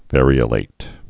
(vârē-ə-lāt, văr-)